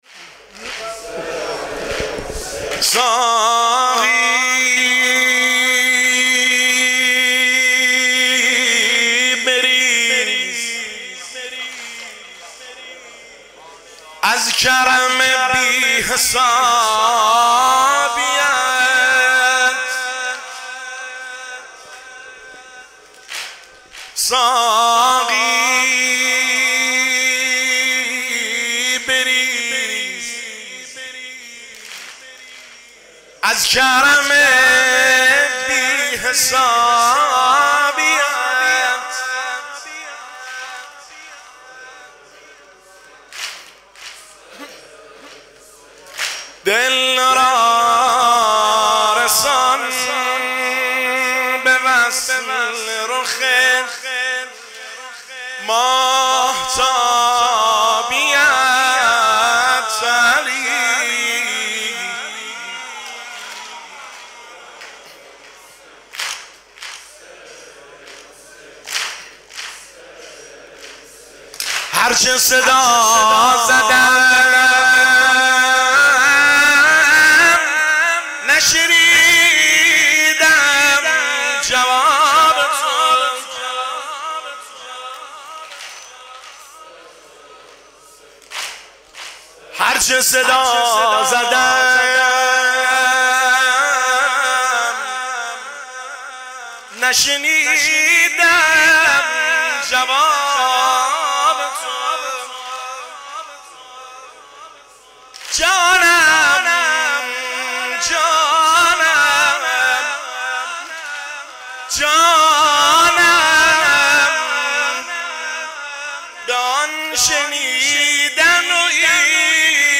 دانلود با کیفیت LIVE